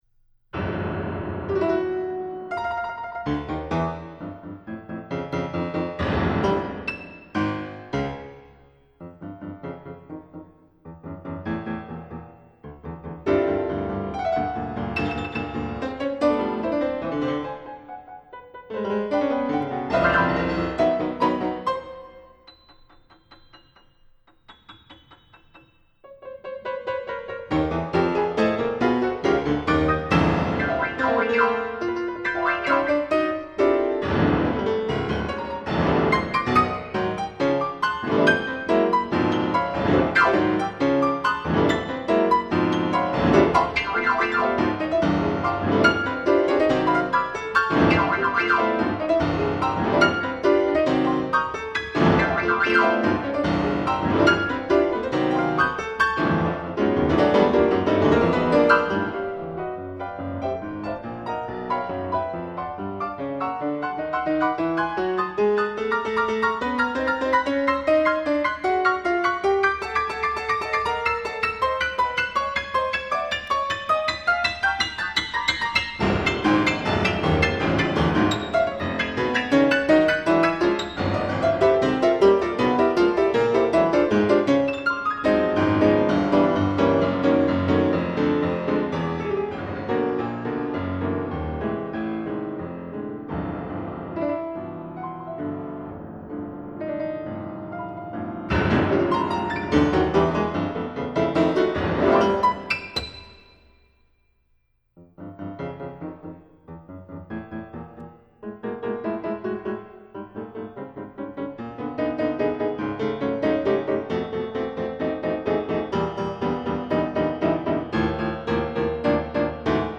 This is a live recording